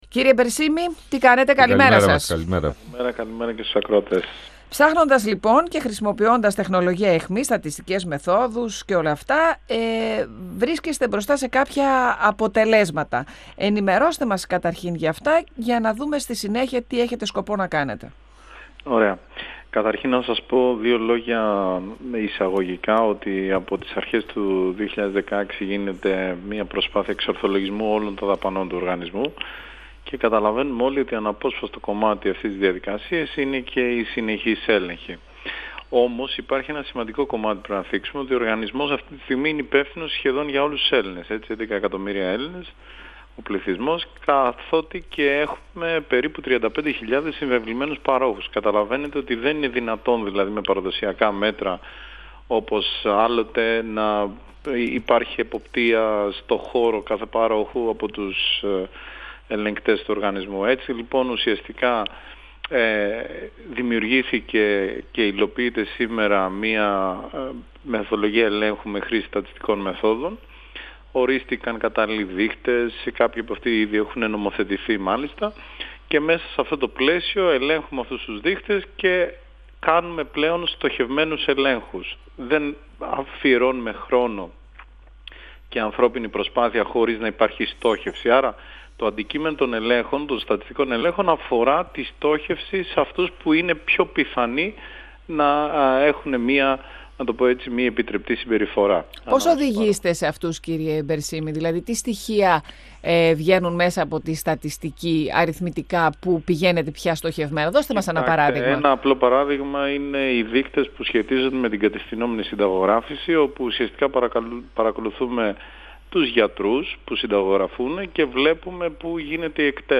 Ο πρόεδρος του ΕΟΠΥΥ, Σωτήρης Μπερσίμης στον 102FM του Ρ.Σ.Μ. της ΕΡΤ3 Ο νέος τρόπος ελέγχου που εφαρμόζει ο ΕΟΠΥΥ φέρνει στο φως σημαντικό αριθμό παραβάσεων, οι οποίες γνωστοποιούνται στην αρμόδια υπηρεσία για περαιτέρω έλεγχο.
Συνεντεύξεις